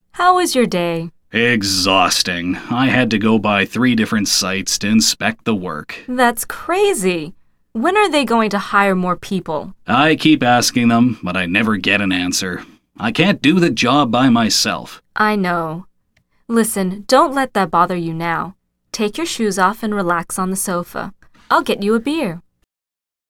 精選對話→